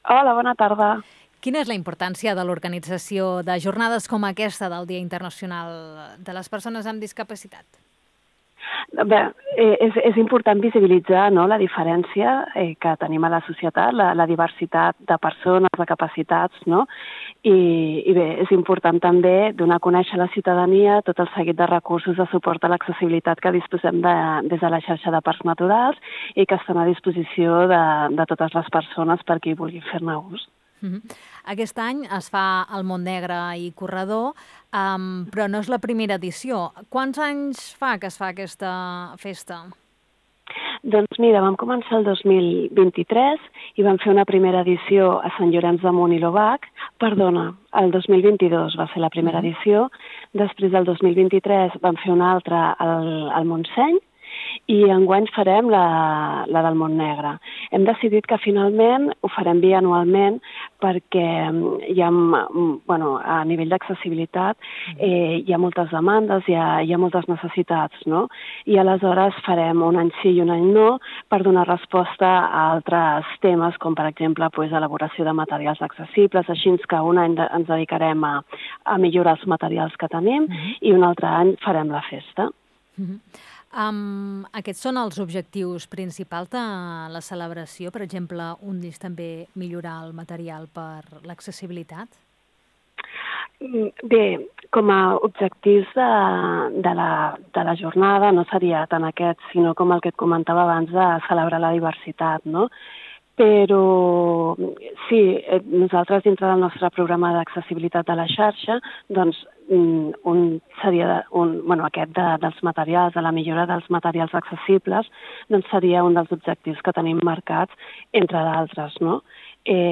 L’entrevista, d'avui a Ona Maresme, destaca la celebració d’una jornada festiva als entorns del Santuari del Corredor, al Parc del Montnegre i el Corredor, amb motiu del Dia Internacional de les Persones amb (Dis)capacitat.